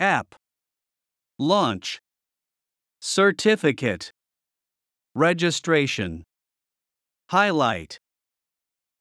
launch /lɔːntʃ/（動）開始する
certificate /sərˈtɪfɪkət/（名）証明書、免許状